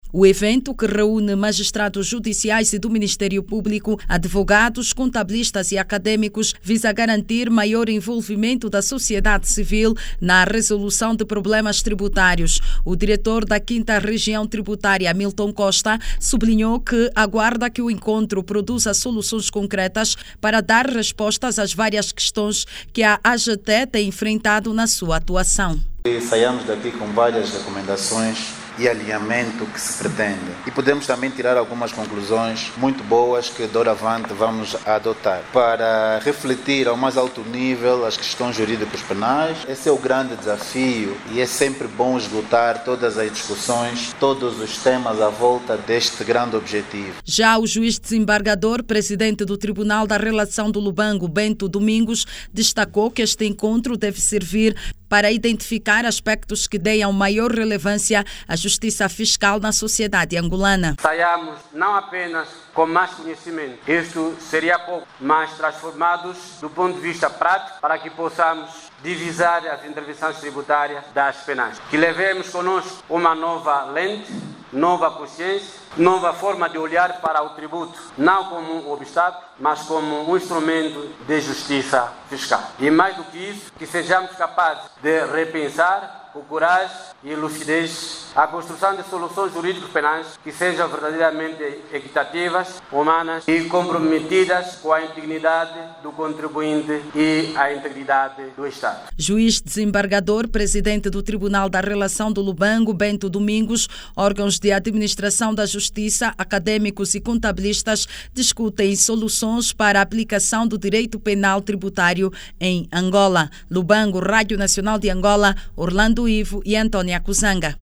A aplicação Direito Penal Tributário, em Angola está em analise na Província da Huíla. Um dos objectivos do encontro, é a busca pela justiça fiscal, para garantir integridade do contribuinte e do estado. Ouça no áudio abaixo toda informação com a reportagem